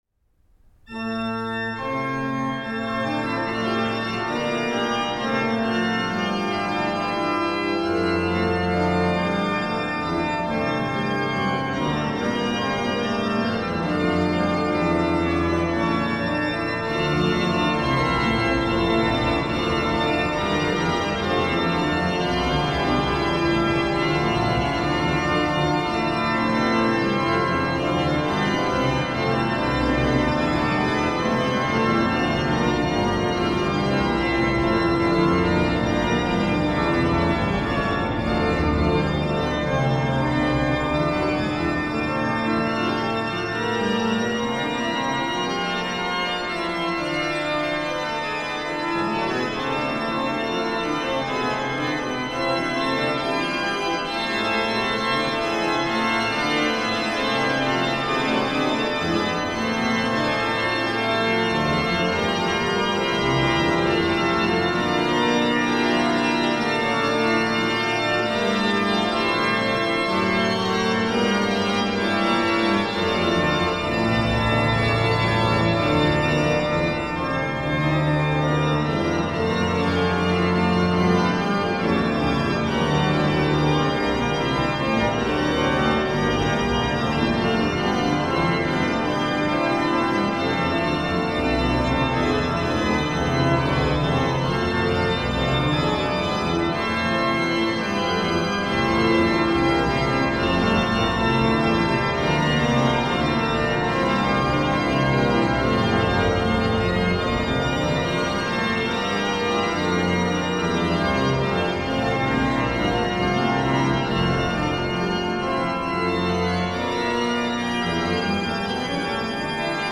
Hinsz-orgel,
Bovenkerk Kampen